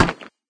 metalstone3.ogg